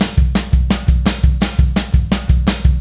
Indie Pop Beat Intro 02.wav